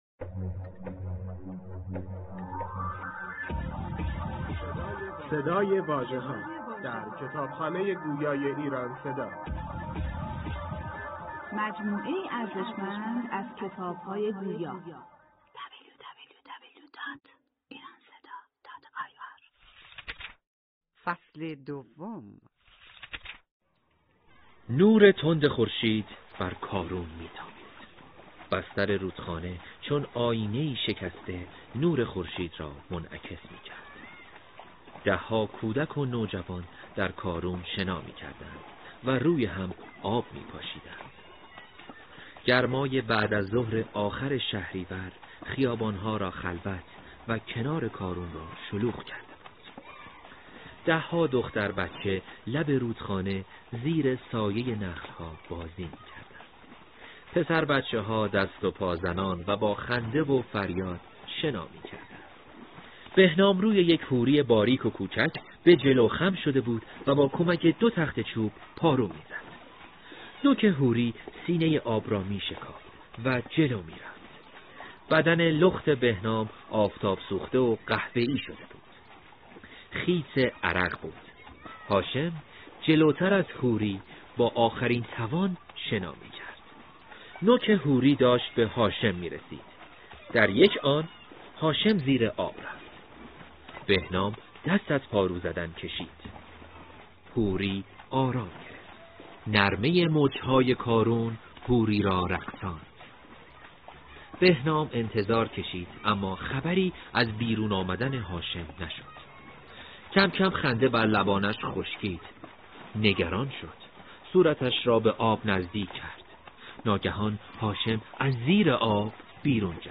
به گزارش نوید شاهد، کتاب «داستان بهنام» به کوشش «نشر شاهد» چاپ شده و توسط «ایران صدا» به فایل صوتی تبدیل شده است.
می‌توانید نسخه صوتی کتاب «داستان بهنام» را از طریق فایل های زیر بشنوید.